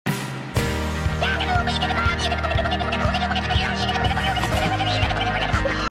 Duck Zoomies feel a lot sound effects free download
Duck Zoomies feel a lot like Donnie Thornberry noises 😂